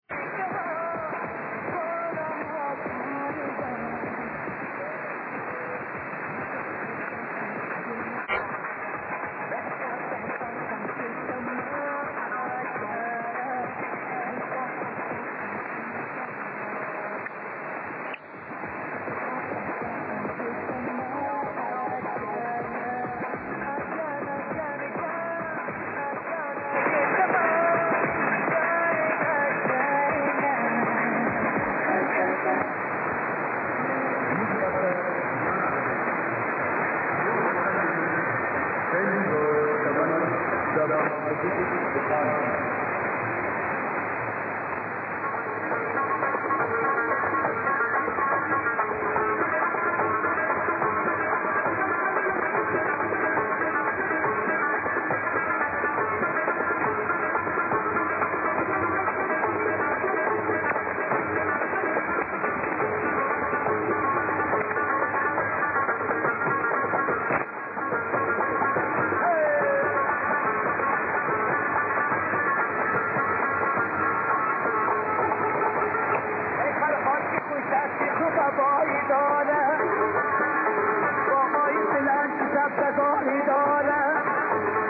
Però mi ha fatto piacere scoprire che entrambe le frequenze arrivavano così bene anche da me, verso le 21.40. Per sentirle, ecco un esempio del sound su 6.700 e su
6.800, la musica è davvero piacevole e ci sono persino degli annunci con l'eco, per chi dovesse capire la lingua dari.